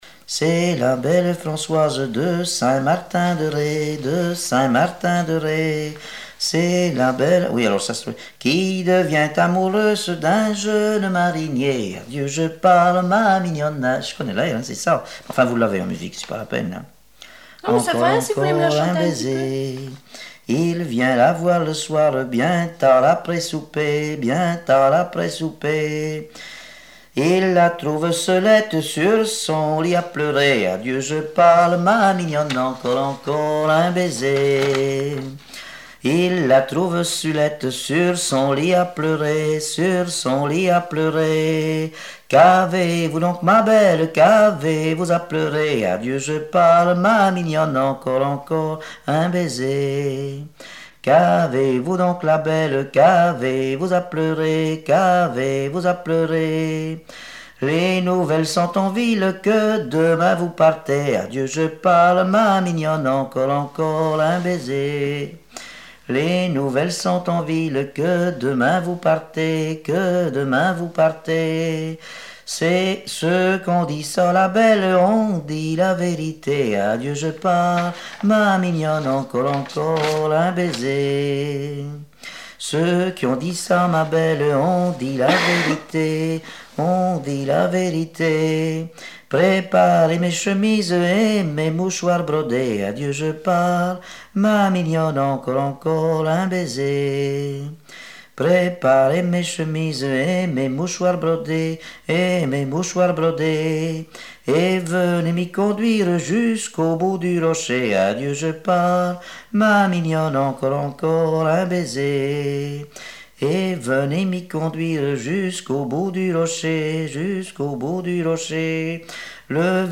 Genre laisse
Répertoire de chansons traditionnelles et populaires
Pièce musicale inédite